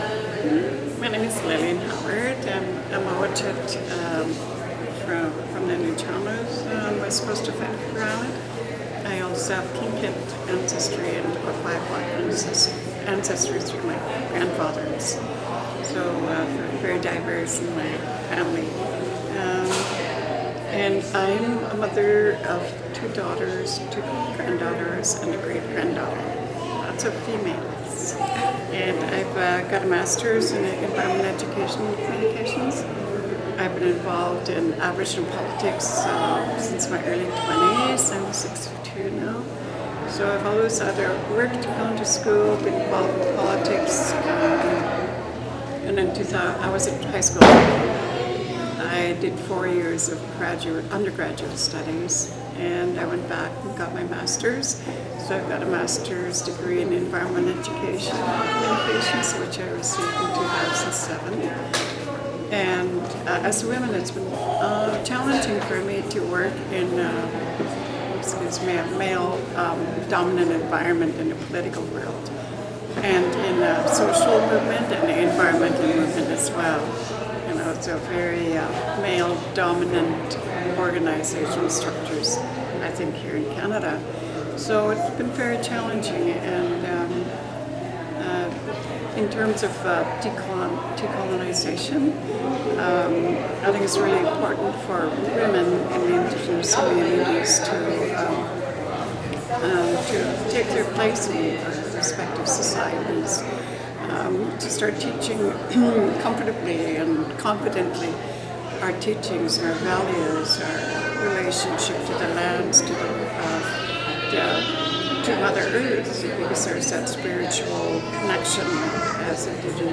INM Indigenous People's Conference